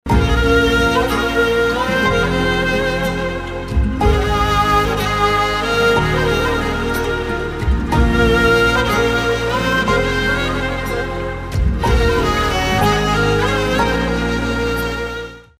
رینگتون احساسی و محزون
(بی کلام)